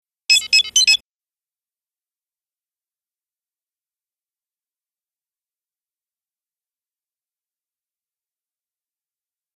Recorded directly from the Kim Possible TV show, here's the Kimmunicator!
I've added about 10 seconds of silence at the end of the sound, so if you use it as a phone call ringtone, it won't play repeatedly.